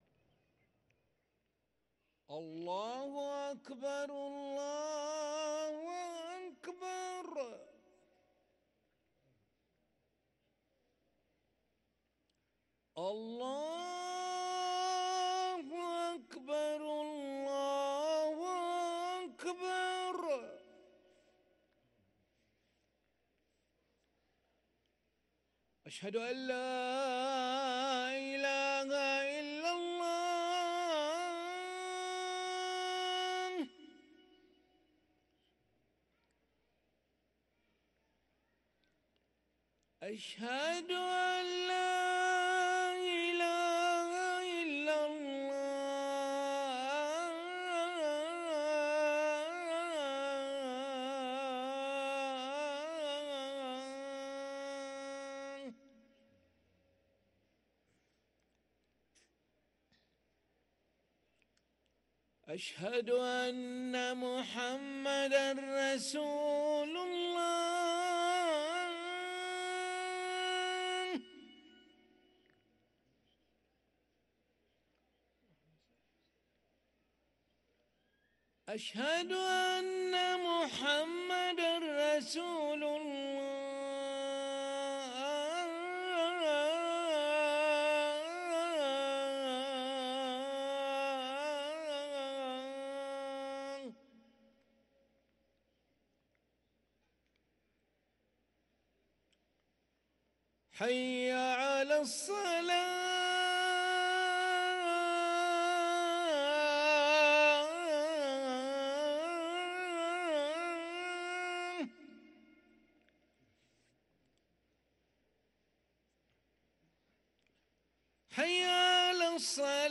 أذان العشاء للمؤذن علي ملا الأحد 22 جمادى الآخرة 1444هـ > ١٤٤٤ 🕋 > ركن الأذان 🕋 > المزيد - تلاوات الحرمين